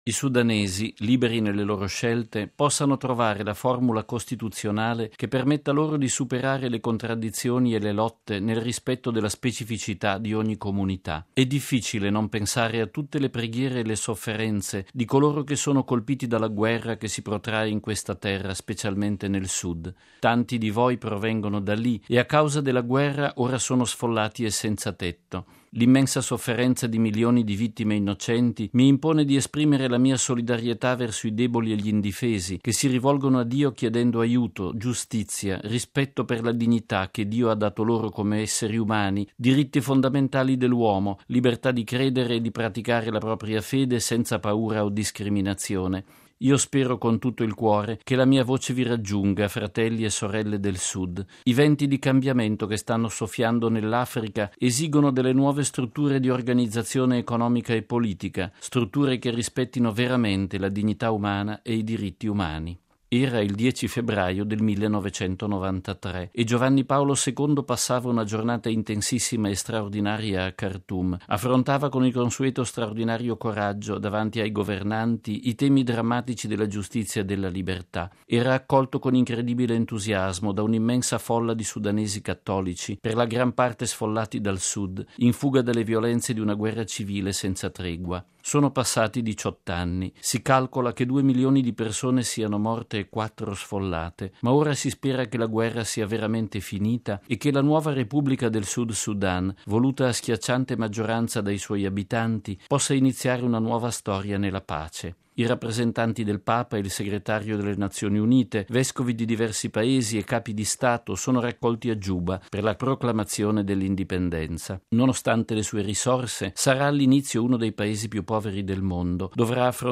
Da Khartoum a Juba: editoriale di padre Lombardi
Riascoltiamo gli auspici e le riflessioni di Papa Wojtyla nell'editoriale del nostro direttore, padre Federico Lombardi, per Octava Dies, il settimanale informativo del Centro Televisivo Vaticano: